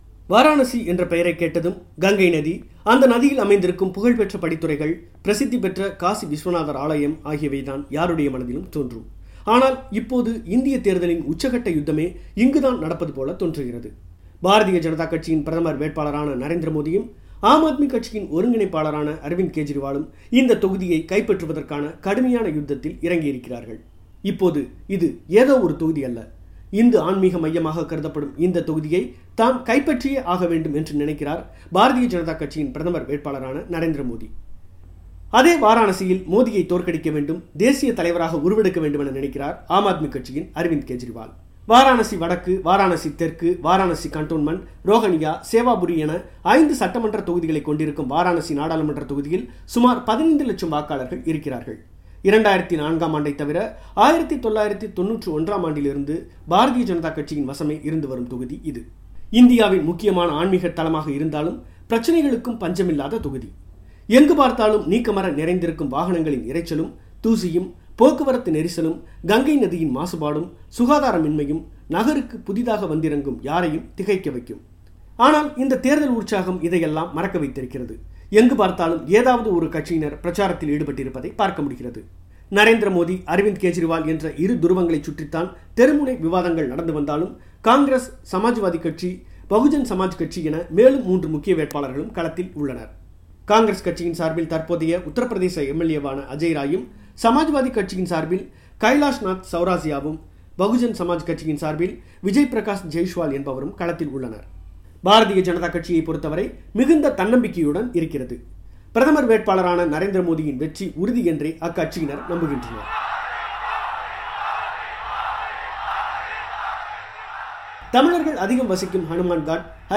அனல் பறக்கும் வாரணாசி தேர்தல் களம் : சிறப்பு பெட்டகம்